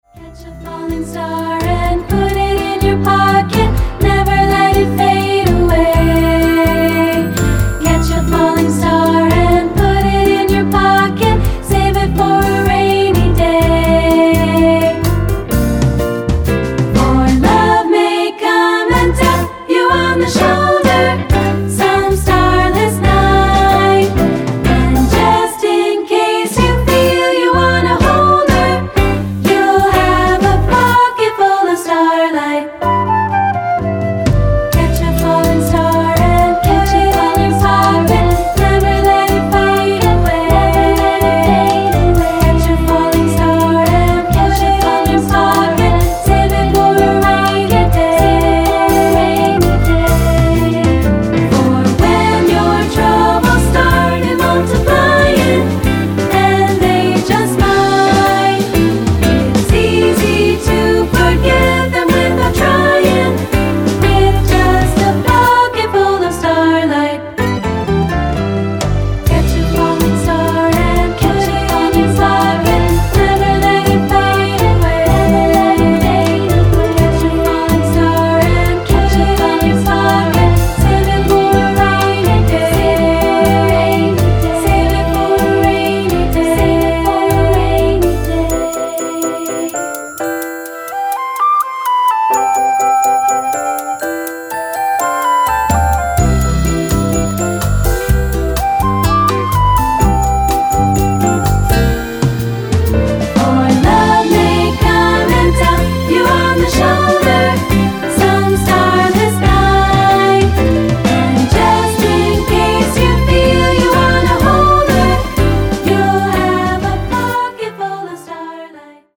Unis/2 Part
Choral Jazz Light Concert/Novelty
A simple bossa nova chorus giving way to jazzy swing verses